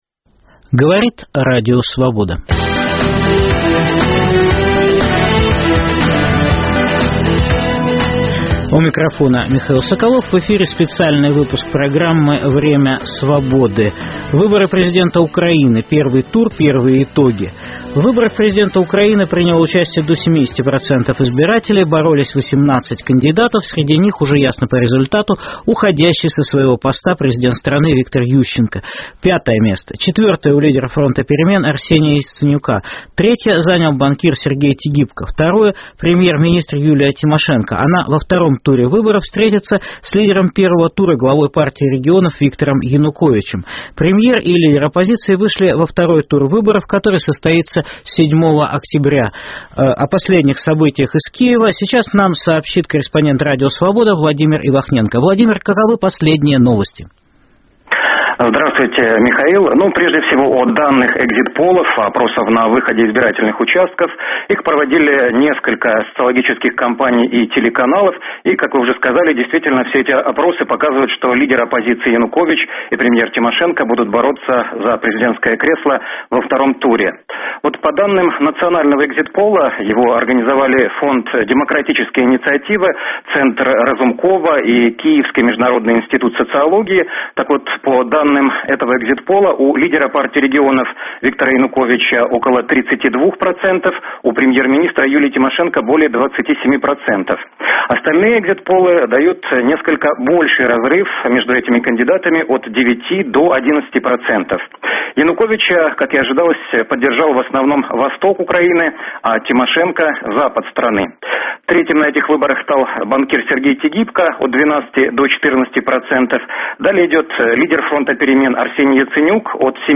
В прямом эфире - подведение итогов украинских выборов